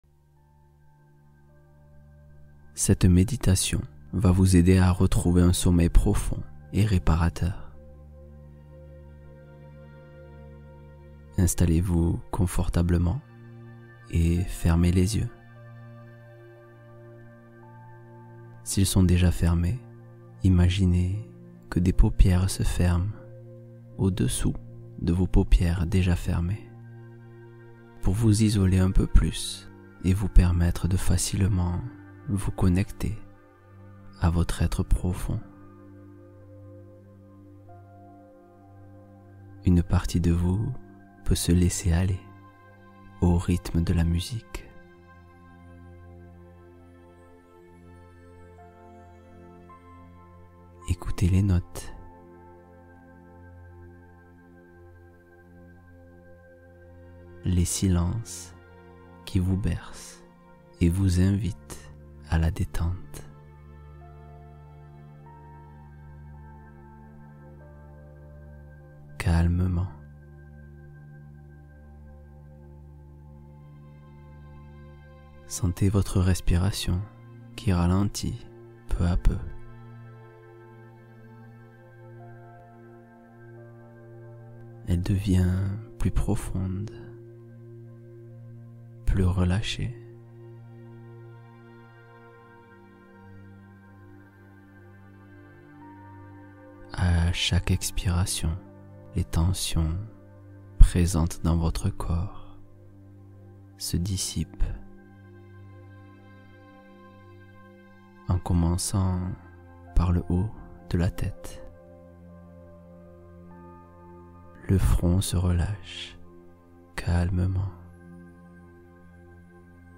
Paix intérieure — Méditation guidée de recentrage